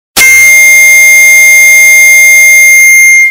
Loud Sound Button - Bouton d'effet sonore